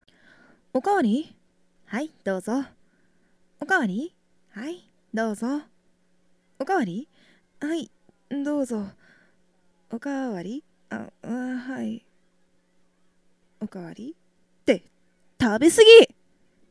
ネット声優に50のお題はこちらお借りしました